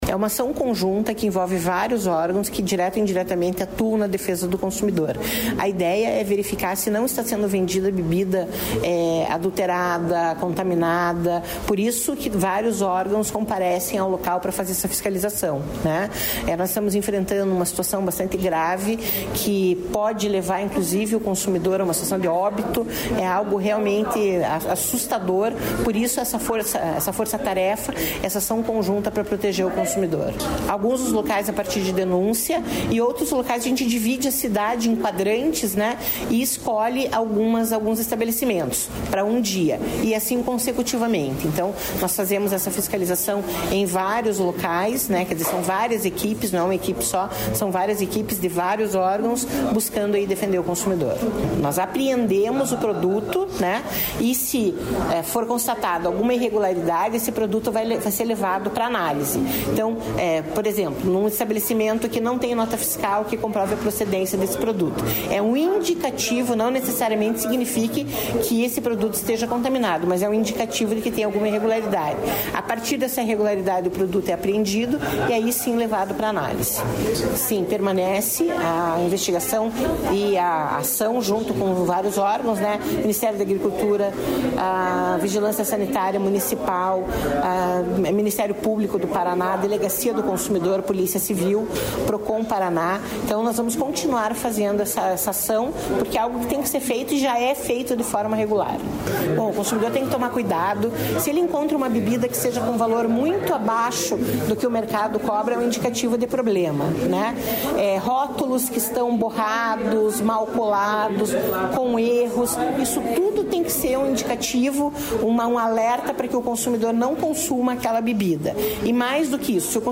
Sonora da coordenadora do Procon-PR, Claudia Silvano, sobre fiscalizações em estabelecimentos que comercializam bebidas